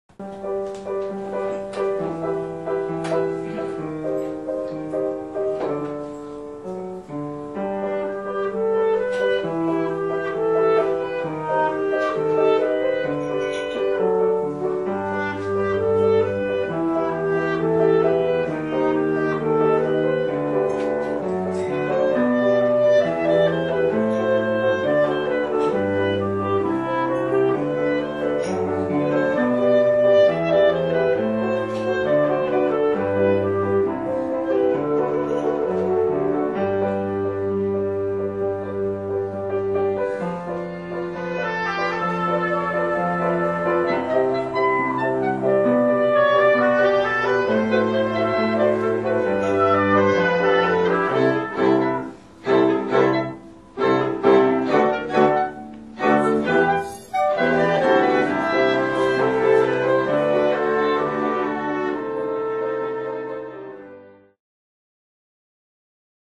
Flute、Oboe、Clarinet、Violin、Cello、Piano